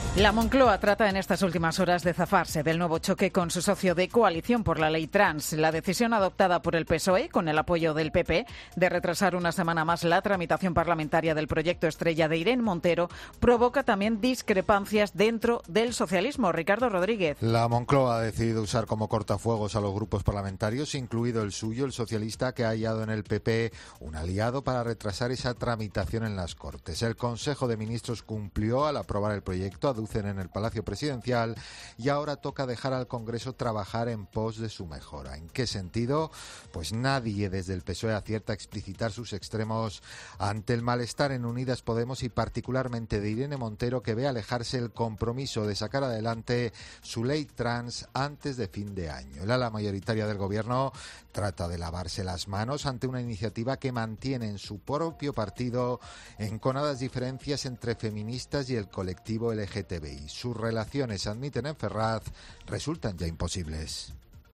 Las discrepancias por la ley trans no se suavizan. Crónica